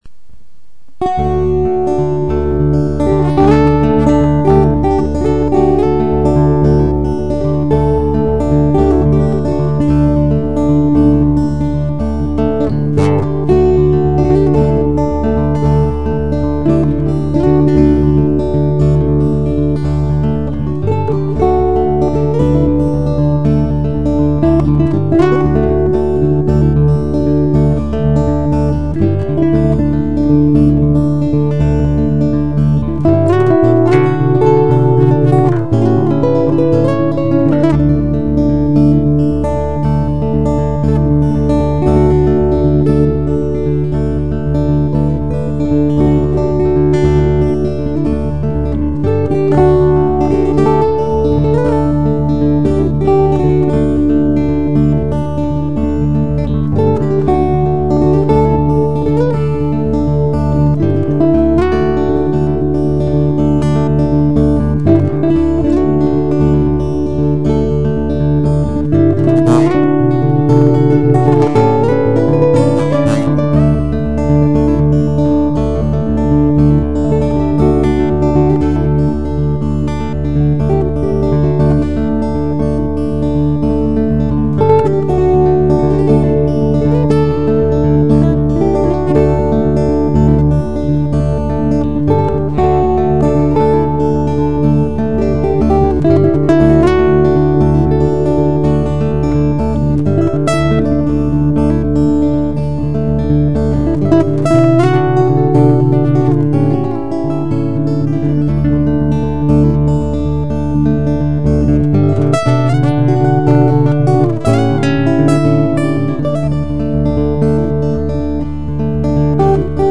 Dieses Lied wurde nämlich erst heute in den Schaumburg Record Studios aufgezeichnet, um bei euch für die richtige Lesestimmung sorgen.